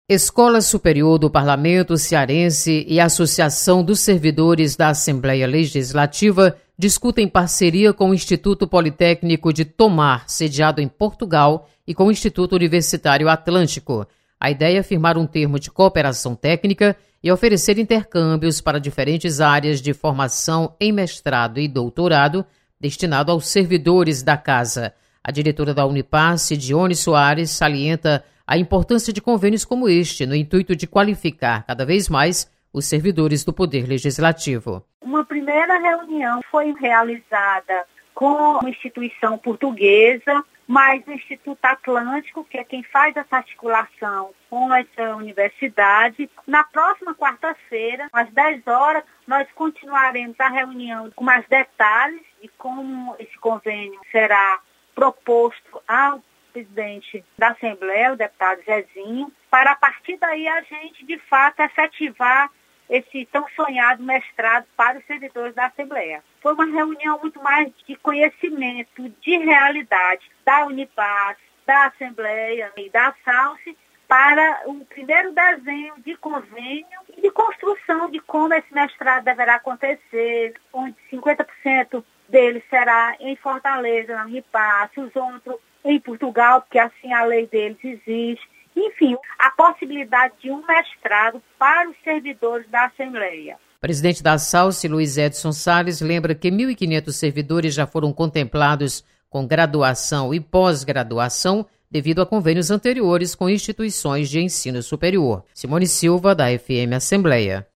Convênio vai garantir pós graduação para servidores da Assembleia. Repórter